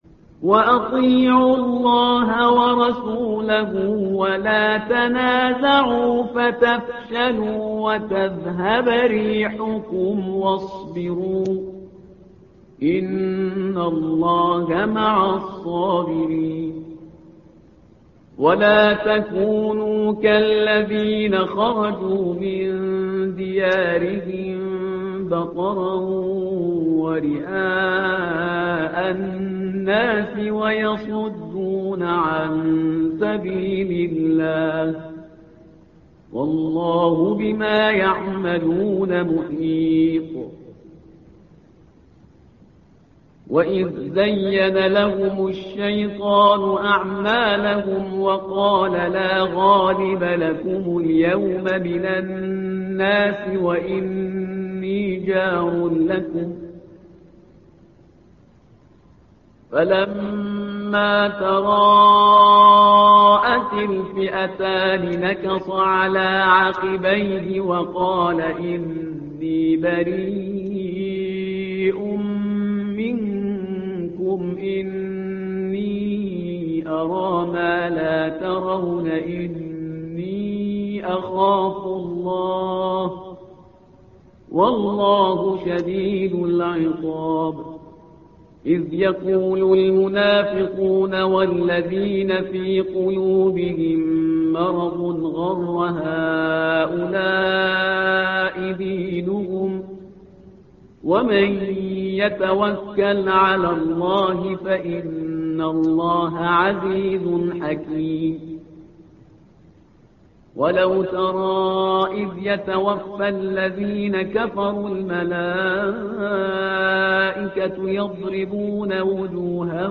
تحميل : الصفحة رقم 183 / القارئ شهريار برهيزكار / القرآن الكريم / موقع يا حسين